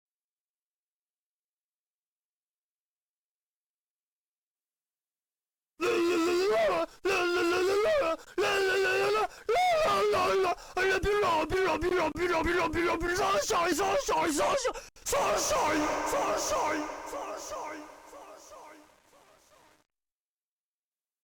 BPM177
Audio QualityPerfect (Low Quality)
CommentsSorry bad audio and No X-scale.